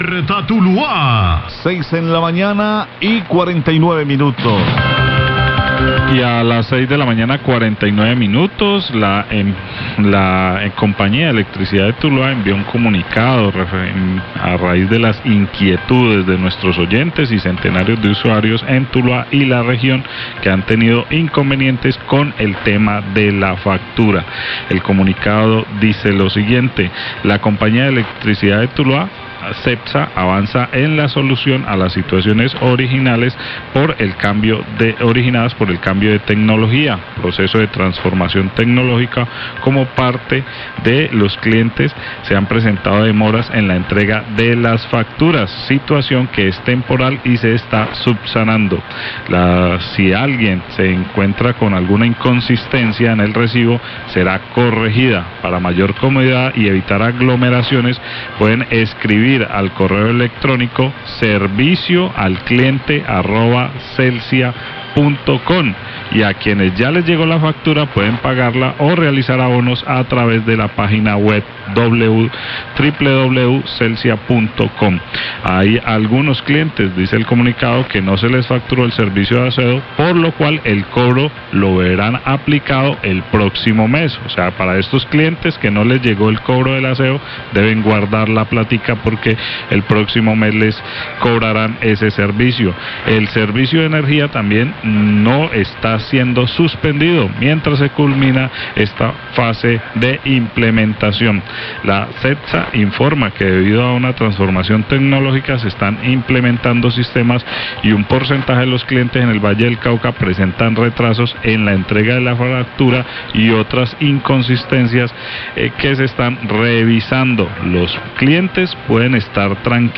Radio
Lectura de comunicado que la empresa Cetsa envió a raíz de las inquietudes de ustedes nuestros oyentes y de centenares de usuarios en Tuluá y la región que han tenido inconvenientes con el tema de la factura: